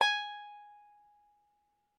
banjo
Ab5.ogg